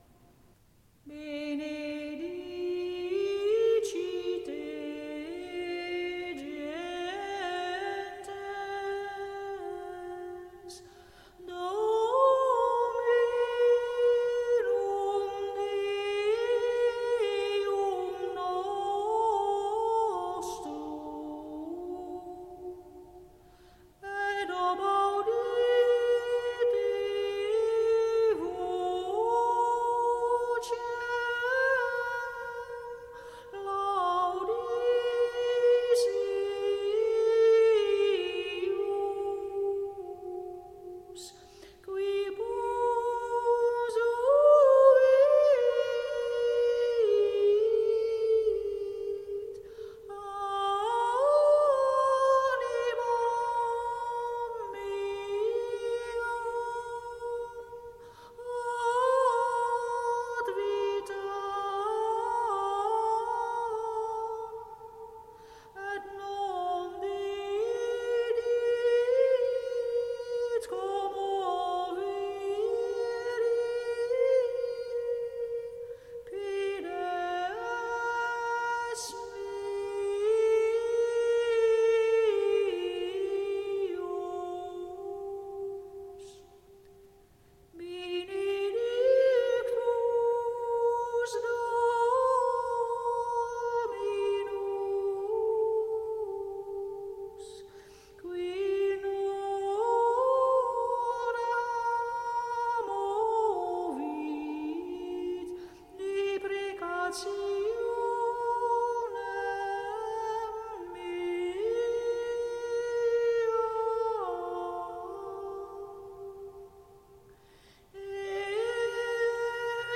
Benedicite, gentes (Ps. 65, 8.9.20), offertorium  WMP   RealPlayer
6eZondagVanPasenOffertorium.mp3